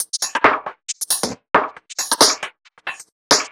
Index of /musicradar/uk-garage-samples/136bpm Lines n Loops/Beats
GA_BeatRingB136-08.wav